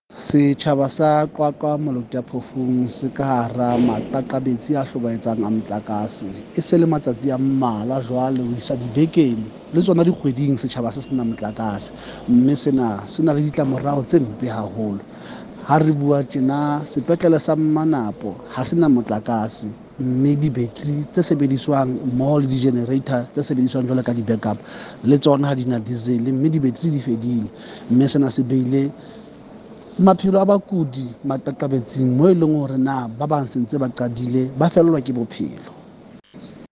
Sesotho soundbites by Cllr Moshe Lefuma.